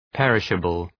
perishable.mp3